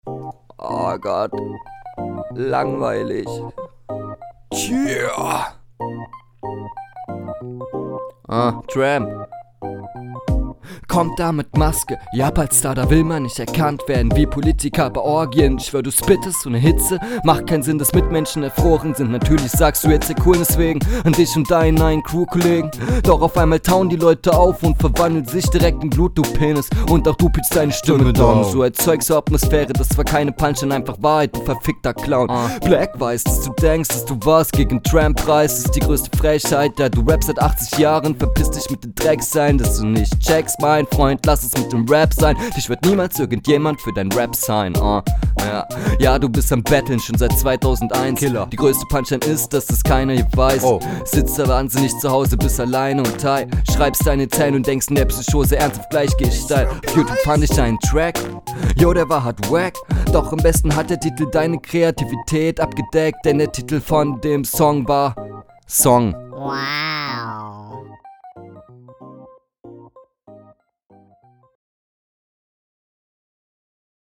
Lustiger Beat!